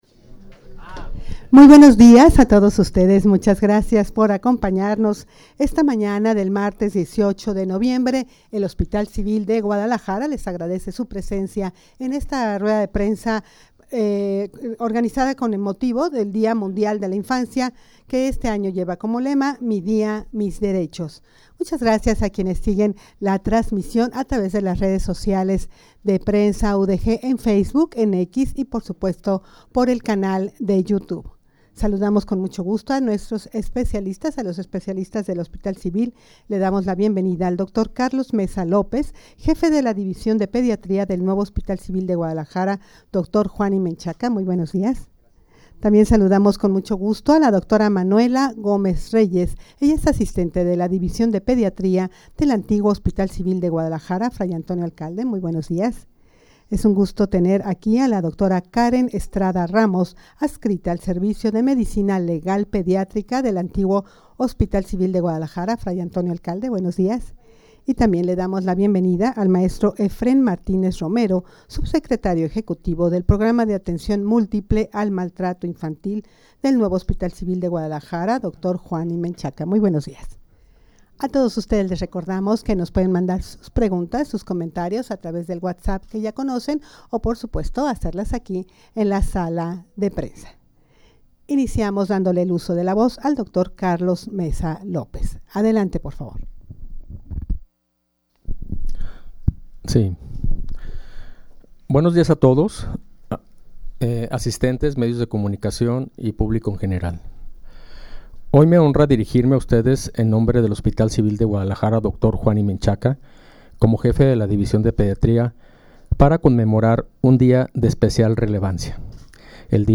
Audio de la Rueda de Prensa
rueda-de-prensa-dia-mundial-de-la-infancia-mi-dia-mis-derechos.mp3